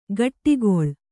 ♪ gaṭṭigoḷ